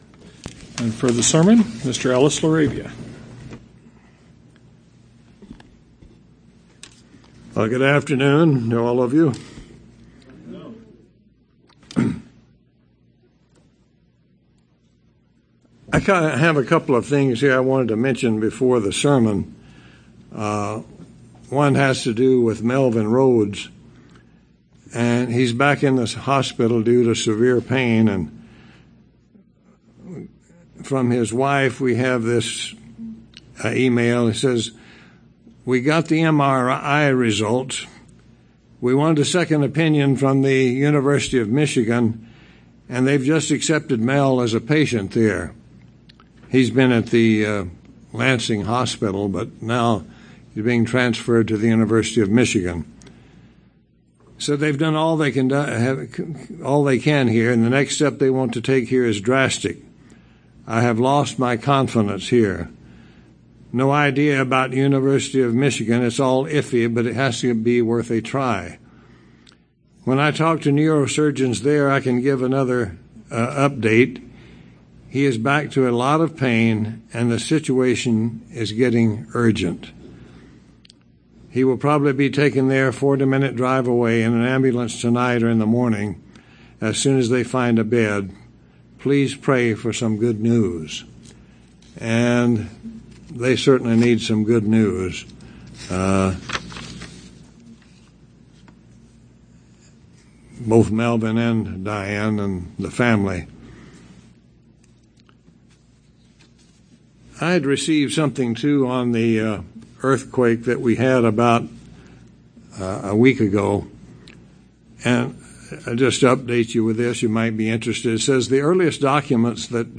In this sermon we are able to explore some of the roles of the WORD. We also explore the oneness of God the Father and Christ.